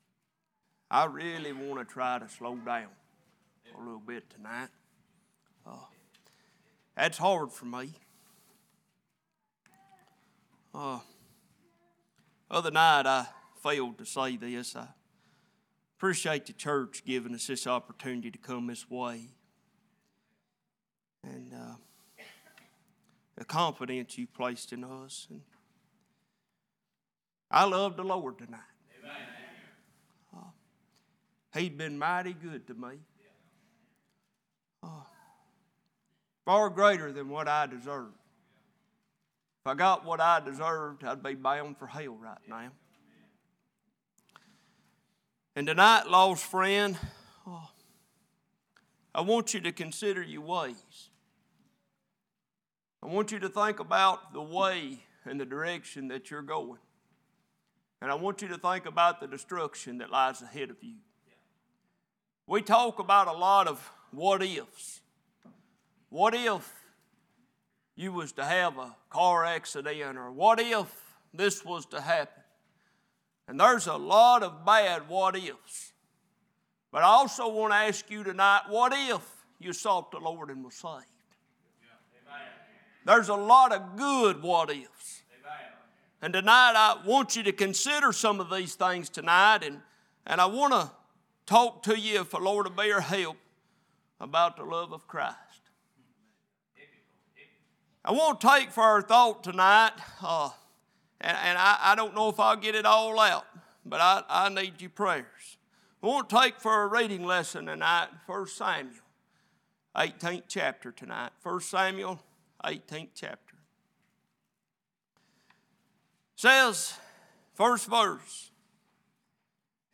Series: Revival Meeting